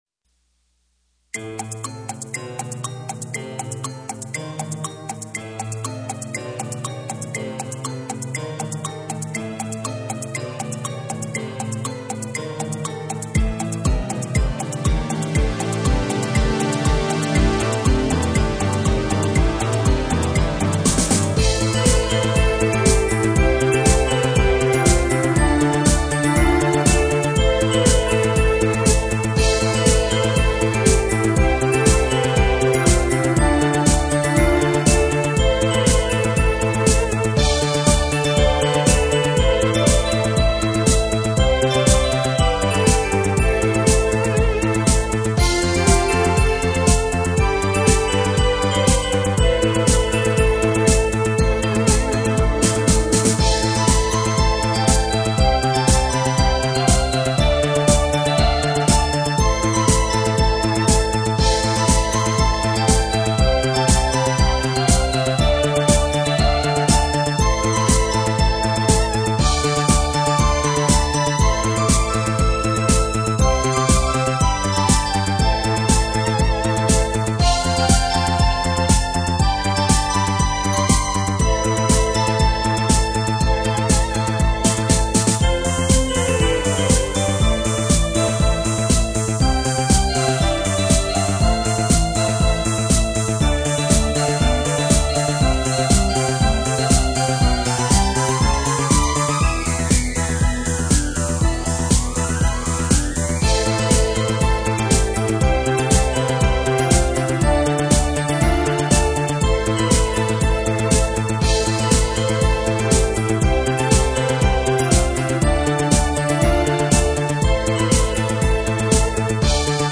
買ったばかりのシーケンサーで打ち込み作ったお遊び曲！